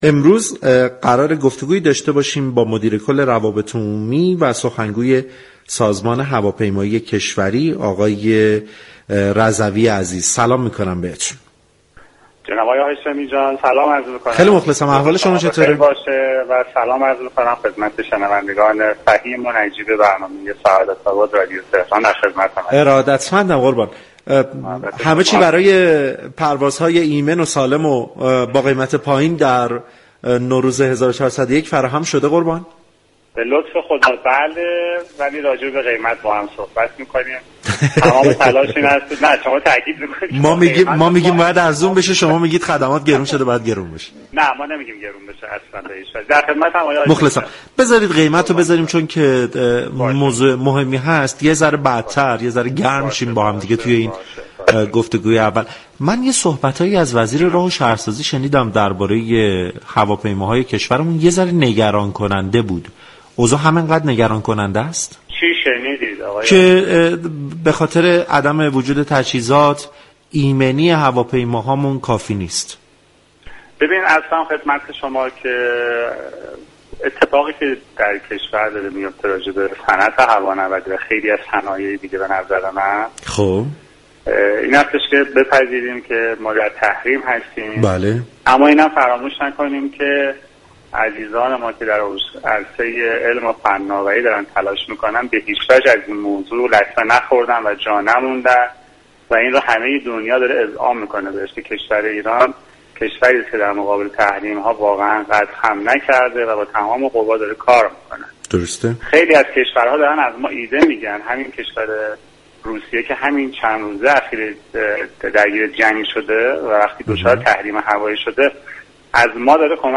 در گفتگو با برنامه سعادت آباد رادیو تهران